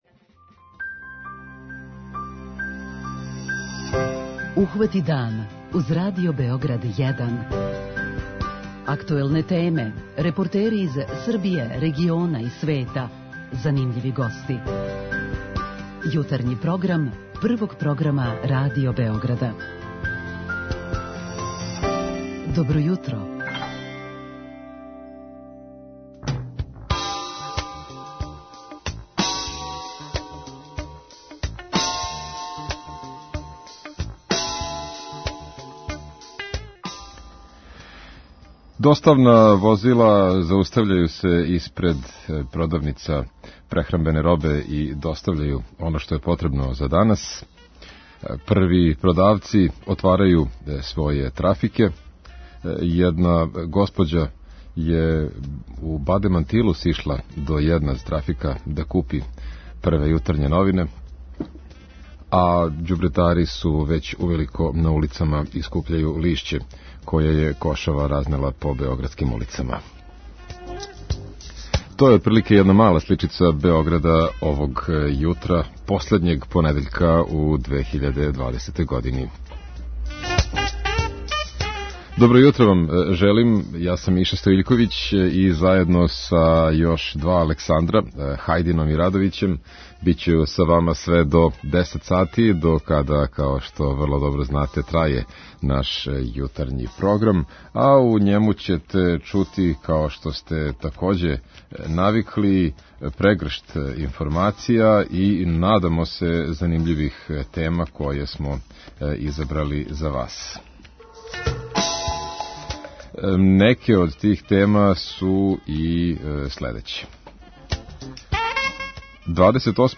Јутарњи програм Радио Београда 1!
Чућемо се с нашим дописницима из Пирота и Кикинде јер ова два града сутра обележавају свој дан.